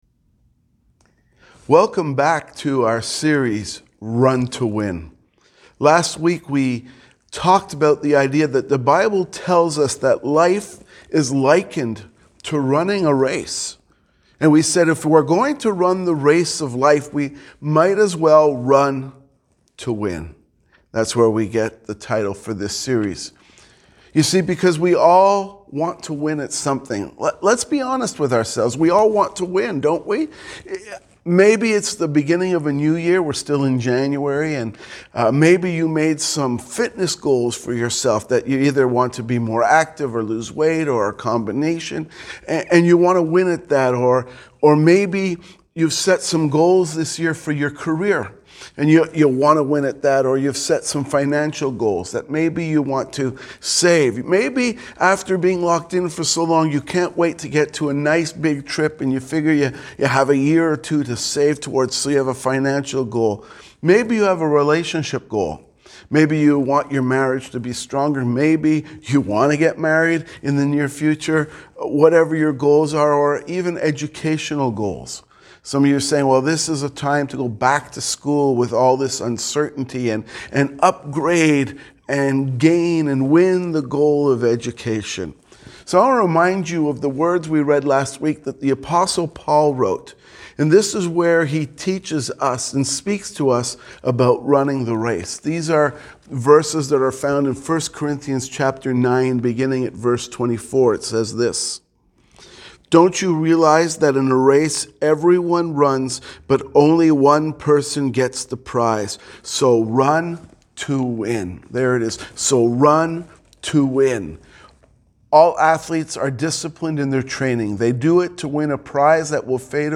Sermons | Highway Gospel Church